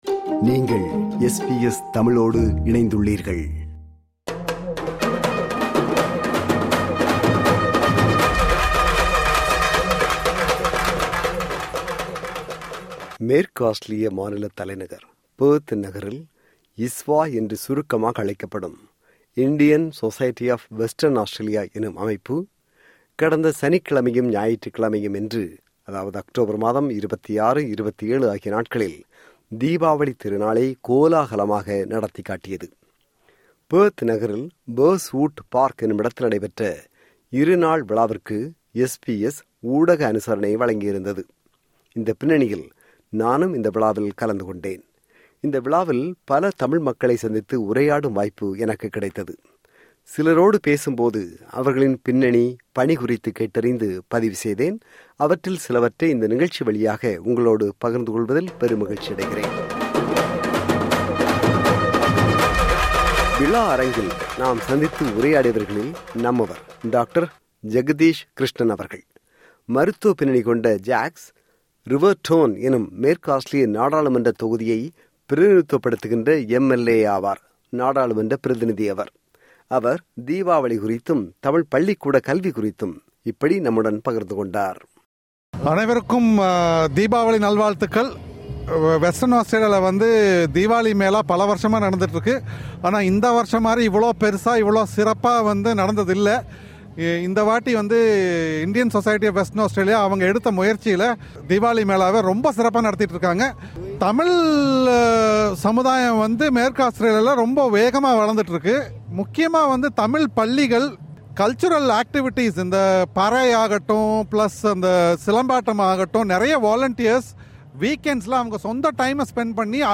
மேற்கு ஆஸ்திரேலிய தலைநகர் பெர்த் நகரில் கடந்த சனிக்கிழமை மற்றும் ஞாயிற்றுக்கிழமை (அக்டோபர் 26 & 27) ஆகிய நாட்களில் தீபாவளி திருவிழா நடைபெற்றது.
சில சமூக தலைவர்களை சந்தித்து உரையாடினார். அந்த பதிவுகளின் தொகுப்பு.